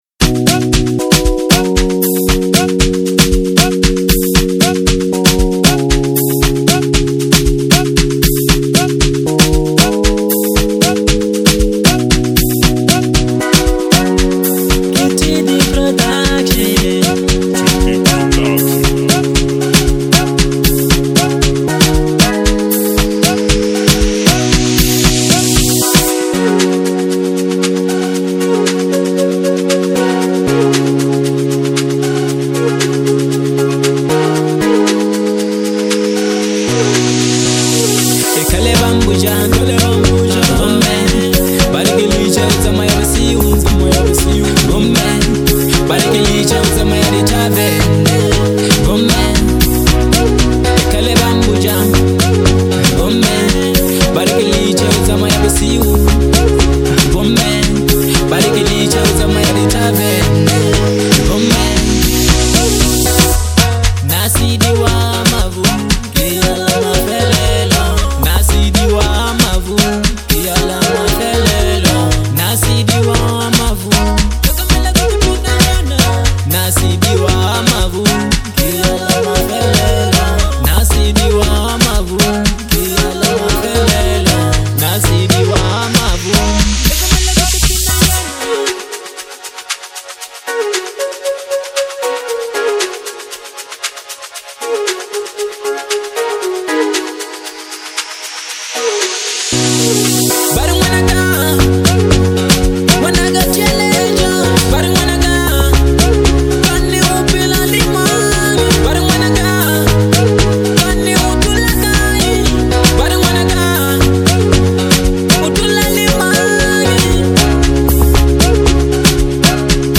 Genre : Bolo House